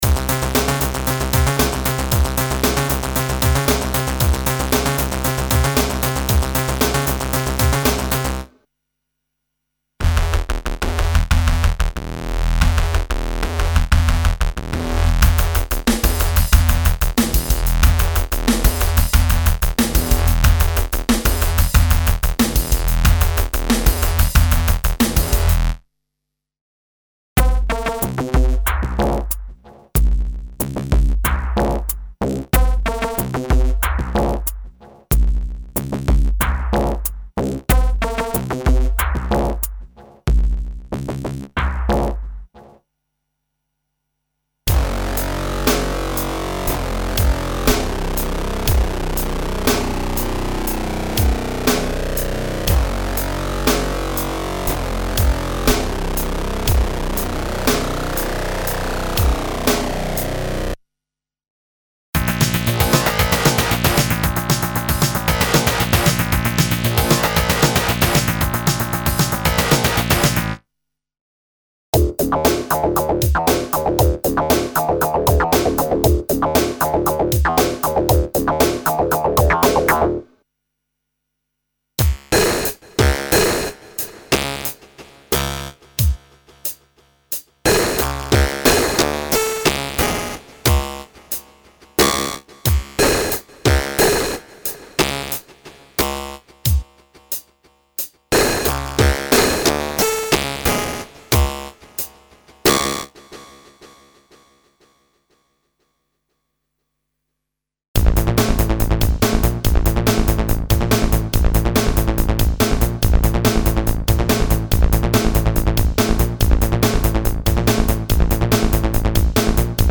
Extreme basses, leads and oscillator waves - aggressive lo-fi sound programs recreating the era of 8-bit samplers and computer sounds, including "paranormal" FM emulations.
Info: All original K:Works sound programs use internal Kurzweil K2600 ROM samples exclusively, there are no external samples used.